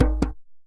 DJEM.HIT13.wav